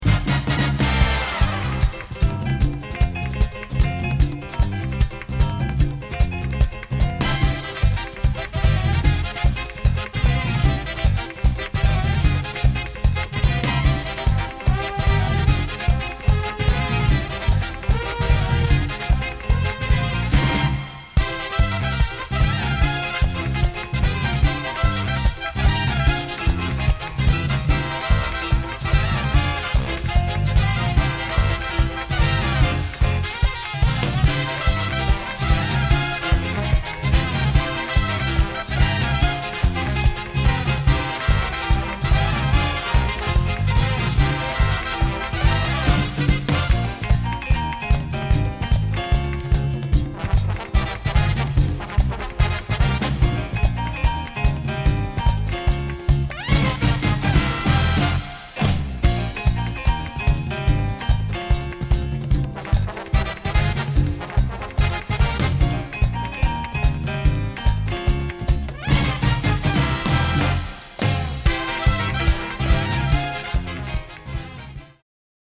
Saxes, Flute, Clarinet
Trumpet, Flugelhorn
Trombone, Euphonium
Piano
Guitars
Bass
Drums
Percussion
Marimba